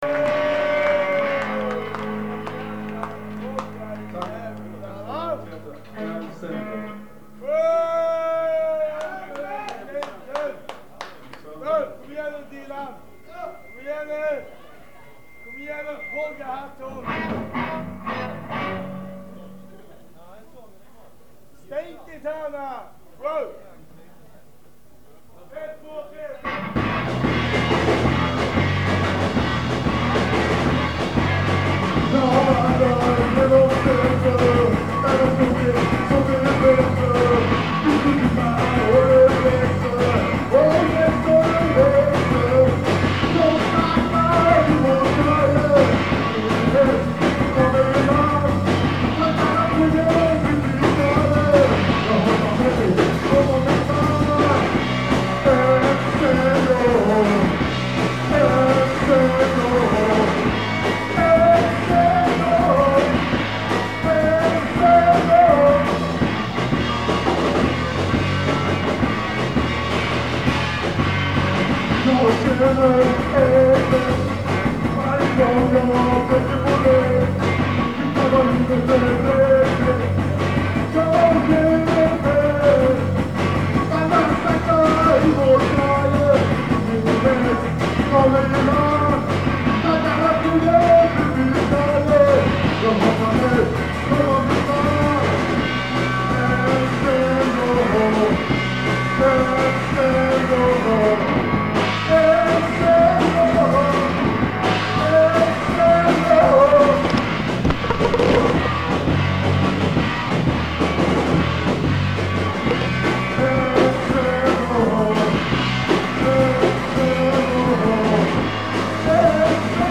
Fredmans, Malmö, Maj -81